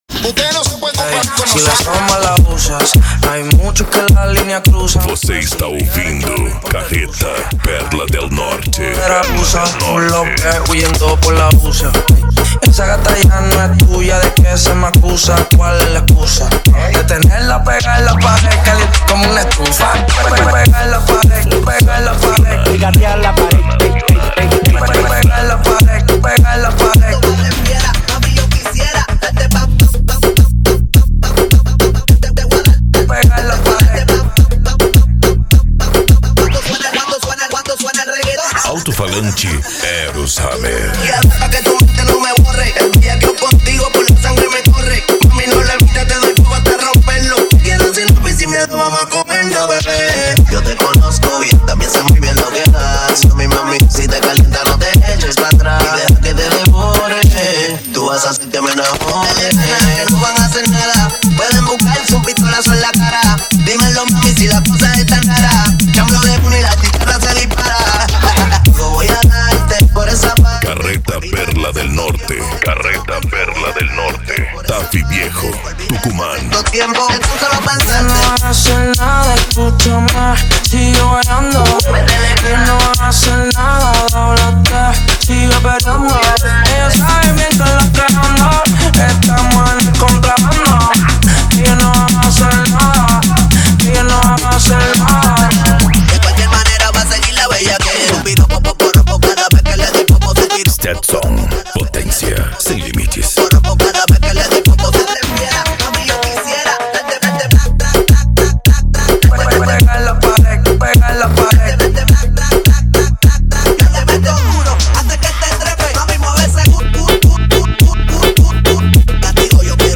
Bass
Remix